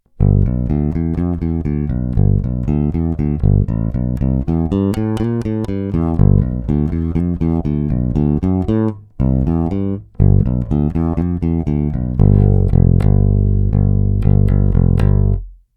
Posuďte (použity jsou struny Thomastik JR344 Jazz Rounds 43 - 89):
Oba snímače po upgradu - MK1 u krku + BC4CBC u kobylky
Poměrně velký rozdíl je znát v kombinaci s krkovým MK1, zvuk obsahuje více středů, možná trochu méně basů, ale do kapely či mixu je to dost dobré.